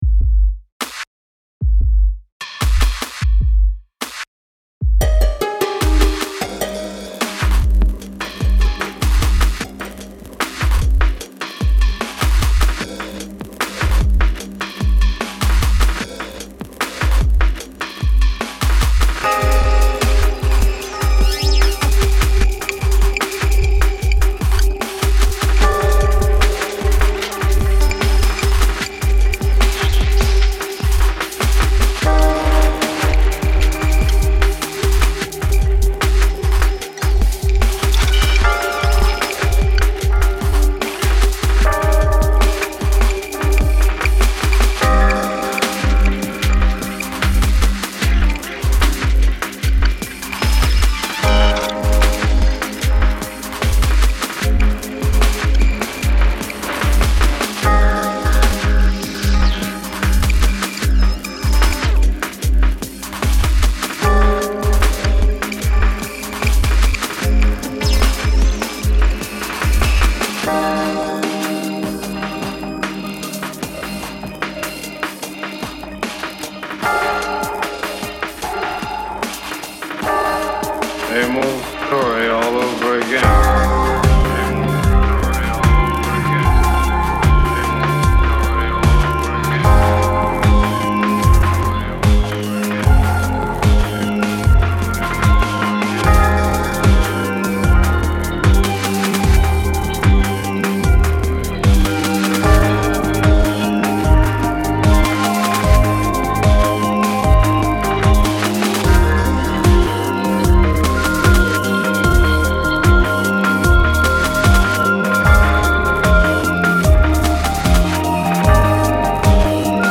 (Melancholic Downtempo)
Учитывая прошлую критику в этом треке я изрядно потрудился над сведением, балансом и мастерингом и поэтому он для меня показателен - на правильном ли я пути.